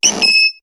Cri de Mélofée dans Pokémon HOME.